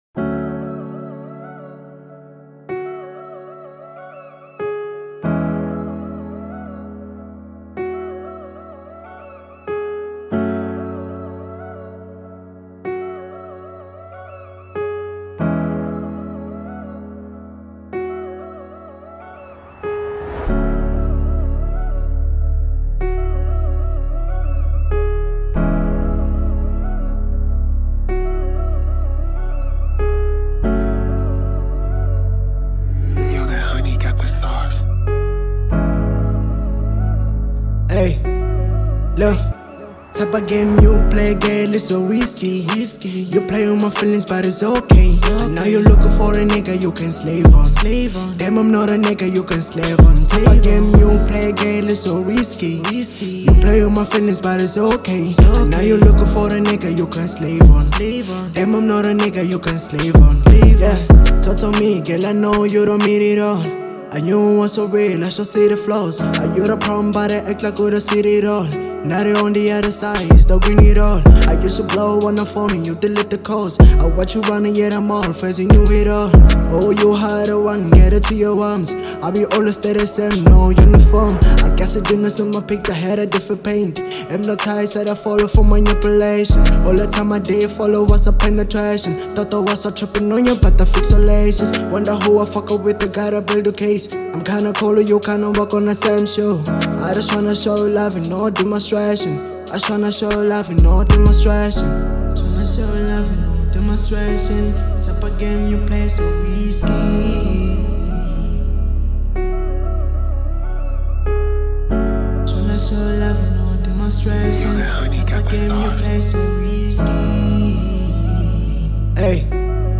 03:30 Genre : Hip Hop Size